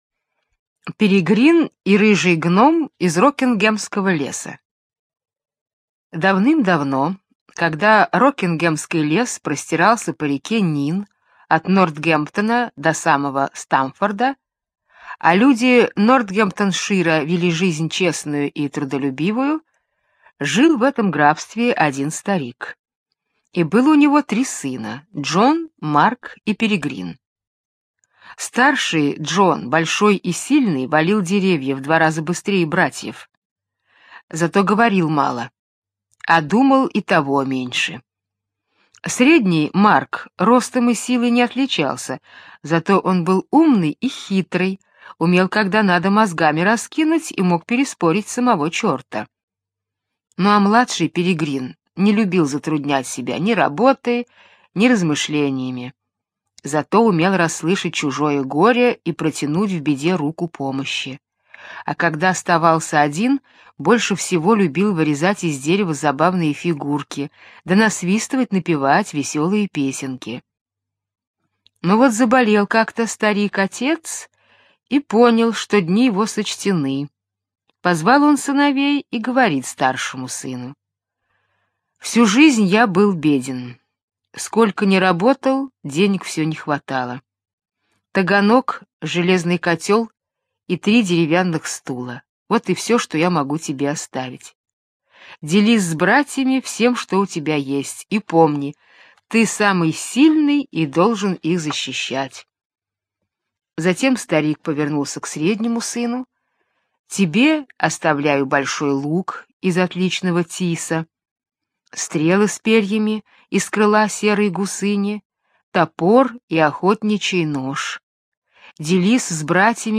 Слушайте Перегрин и рыжий гном из Рокингемского леса - британская аудиосказка.